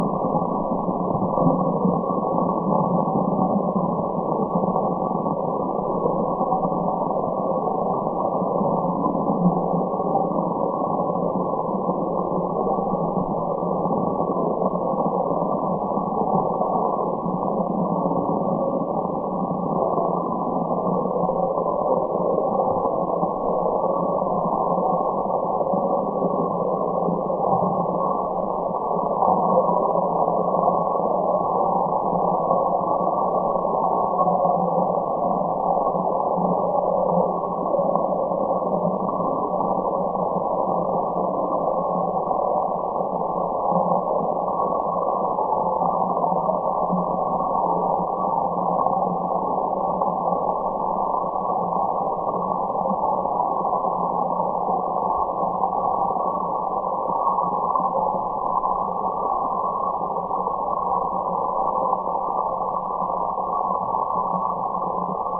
描述：在浅水沼泽地上用棍子划水发出的噪音/agitando el agua con un palo en una charca somera
标签： 场记录 自然 划动
声道立体声